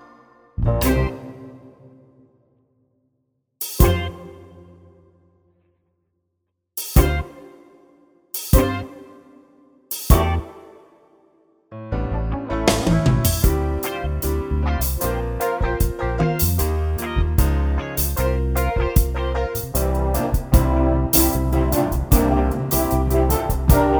no Backing Vocals Musicals 3:36 Buy £1.50